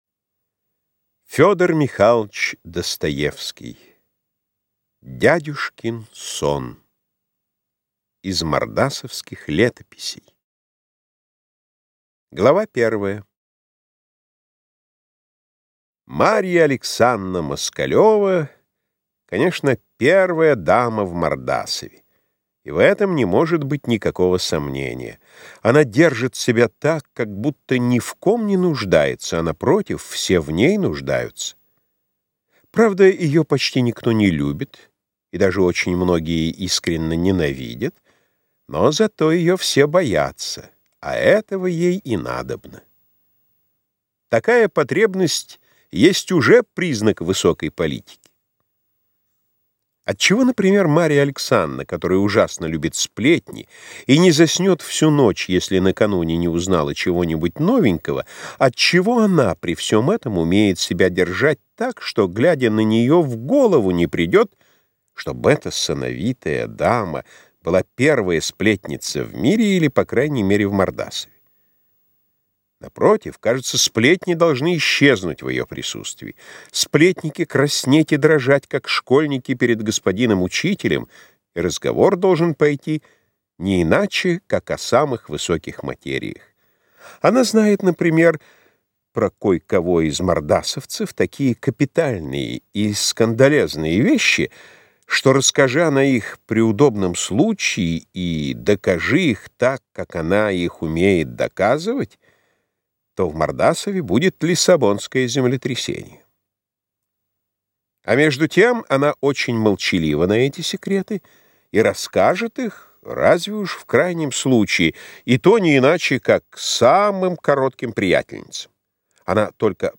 Аудиокнига Дядюшкин сон. Крокодил. Скверный анекдот | Библиотека аудиокниг